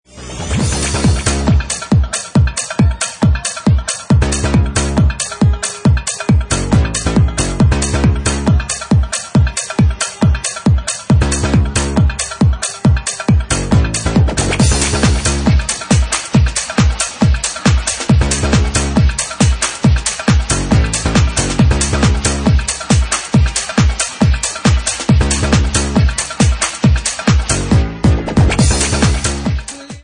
Bassline House at 137 bpm